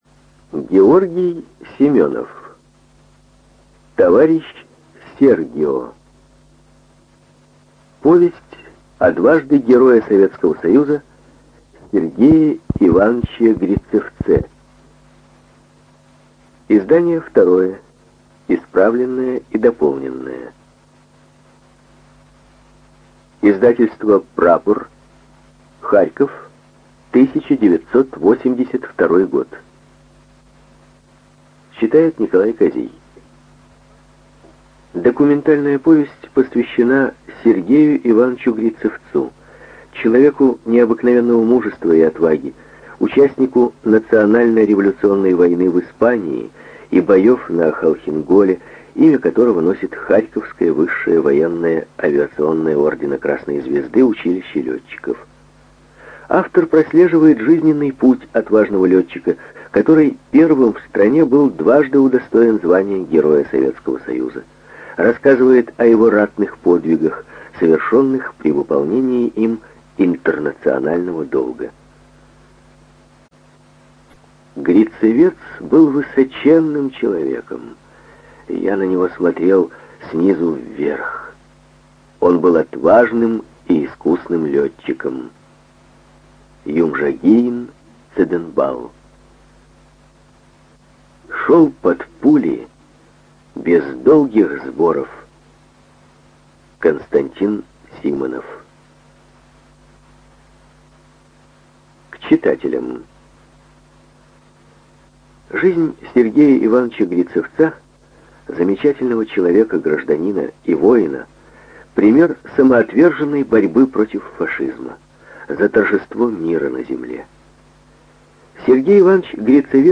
ЖанрВоенная литература
Студия звукозаписиРеспубликанский дом звукозаписи и печати УТОС